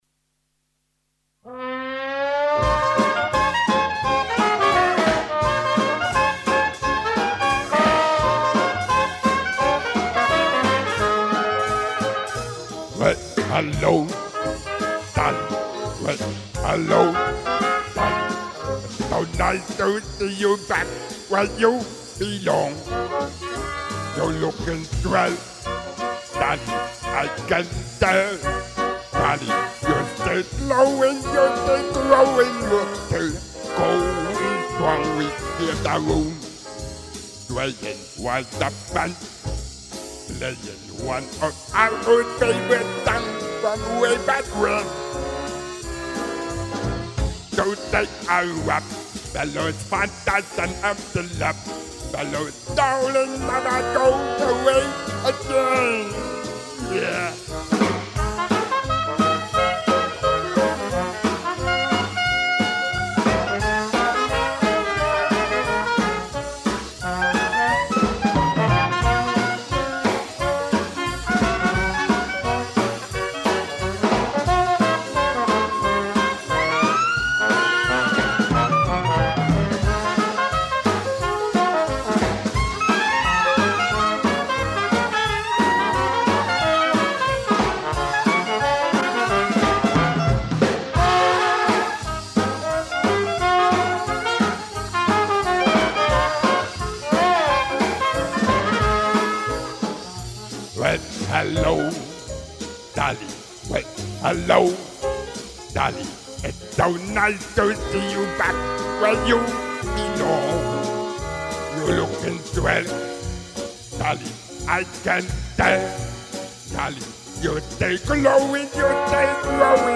Sänger (Bariton bis "Profunder Bass")
Stimmlich wie optisch originell.
Live mit Halbplaybacks!